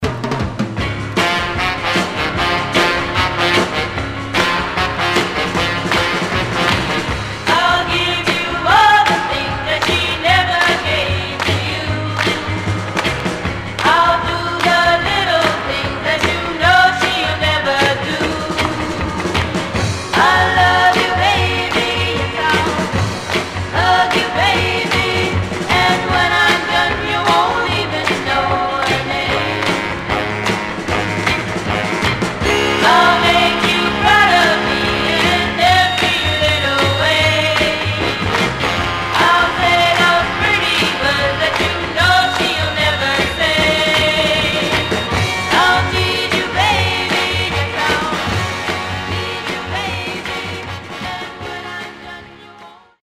Mono
White Teen Girl Groups